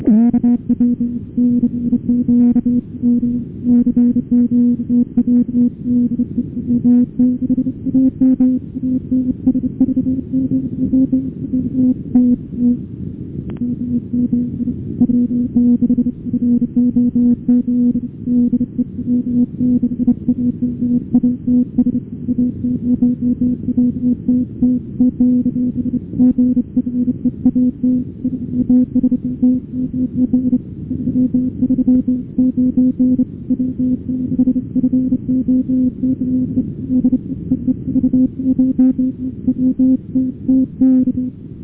20 Meter CW Longpath